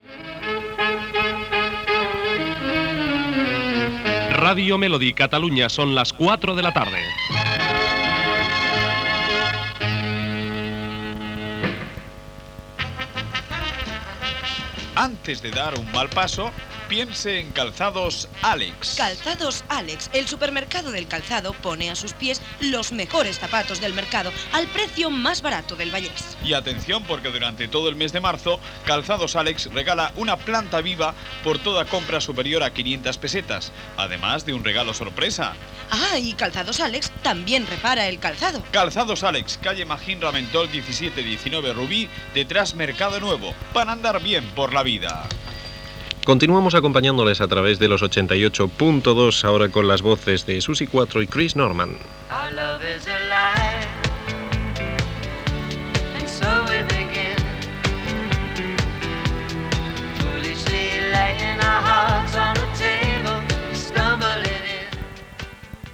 Comercial
Identificació, publicitat i tema musical.
FM